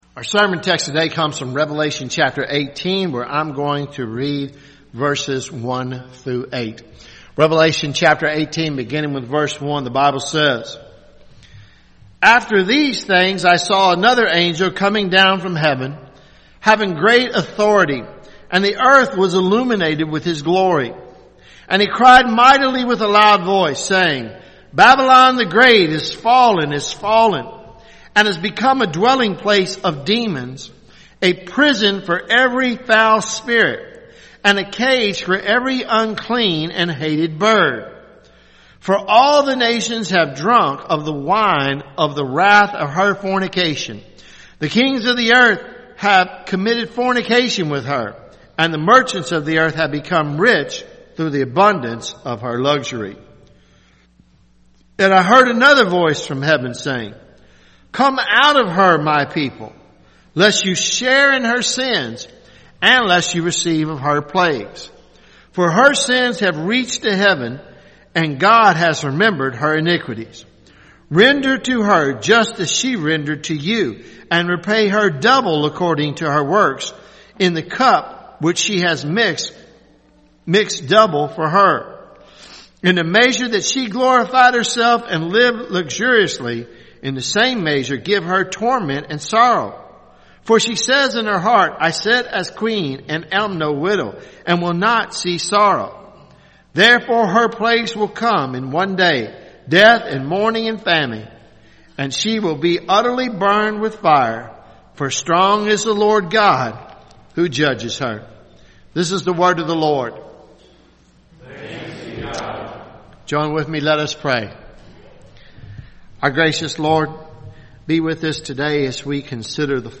Revelation sermon series